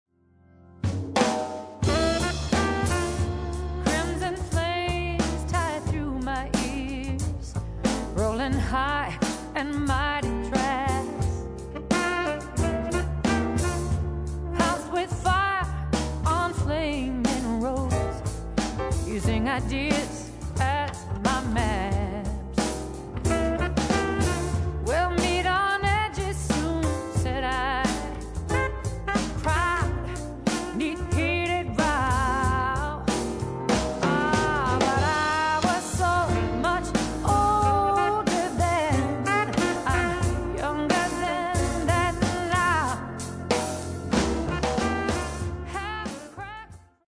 vocals
piano
saxes
bass
drums
guitar
and straight-ahead jazz still create musical magic.